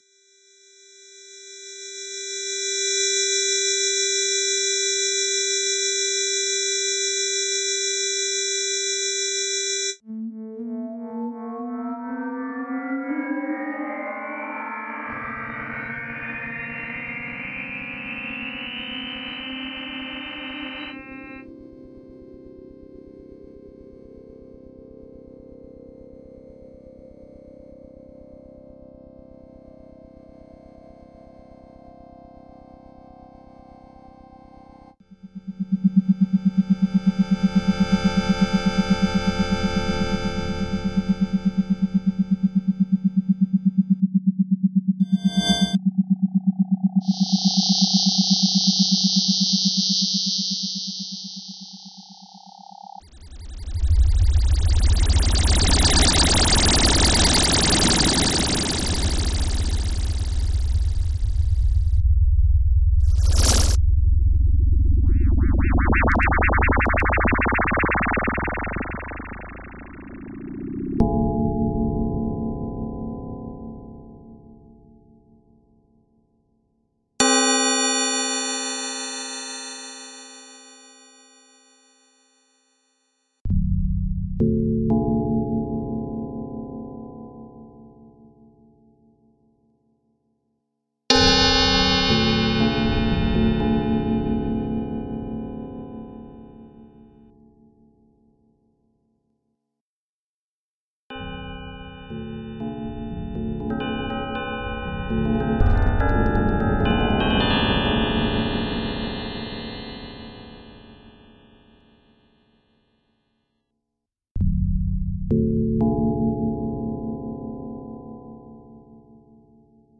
Electronic Pieces